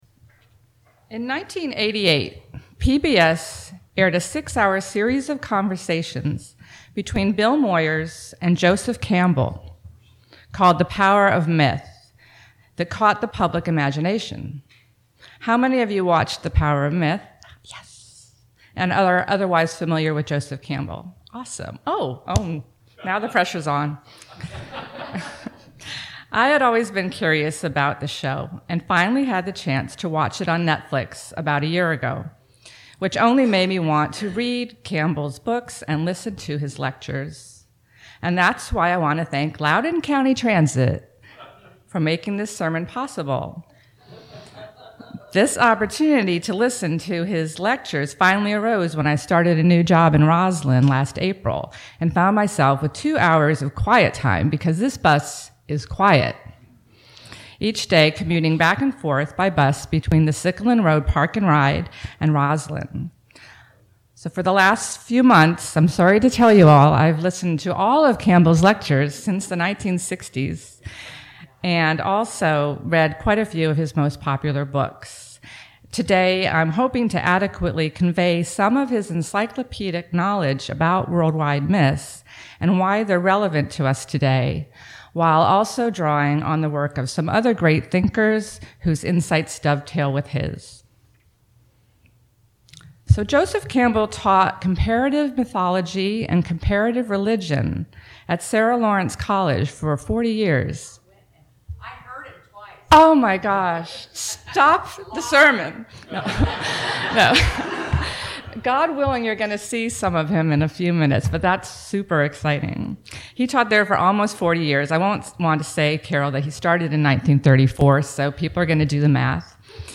This sermon explores the profound influence of Joseph Campbell and his research on comparative mythology as a tool for personal growth.